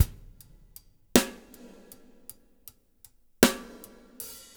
EPH DRUMS -L.wav